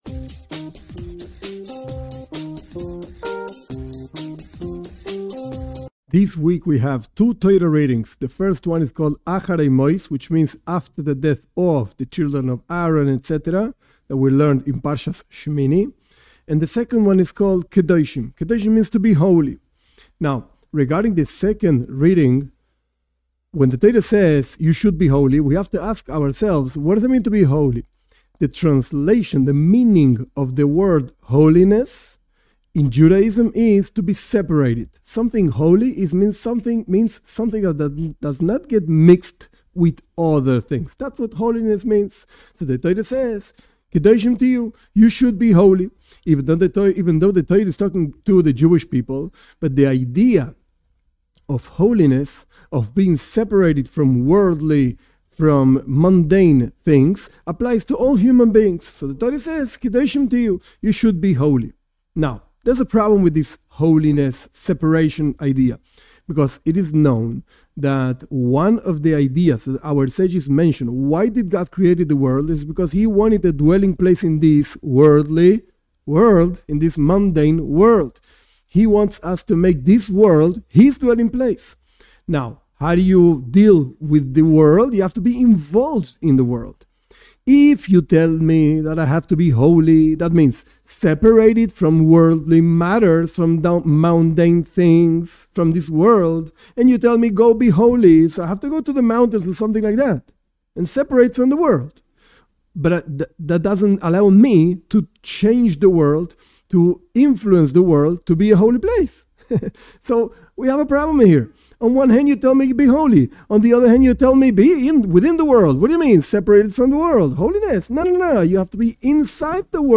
But in this short class we learn that holiness can also mean to be within the world, in a very special way.